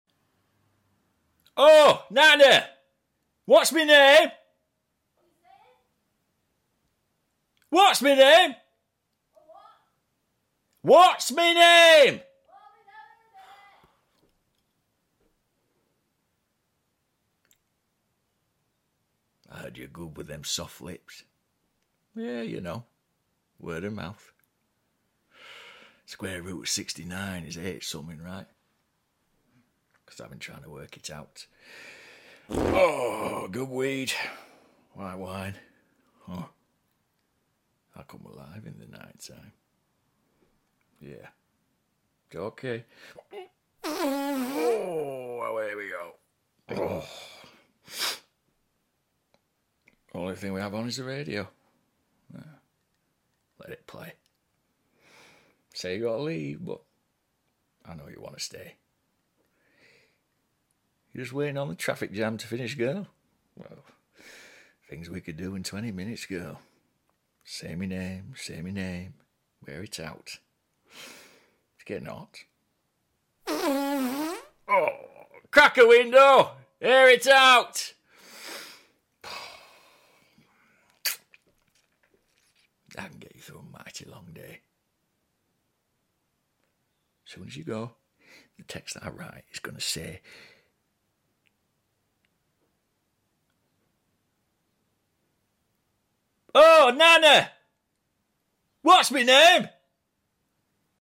🎭 Dramatic Monologue: What’s My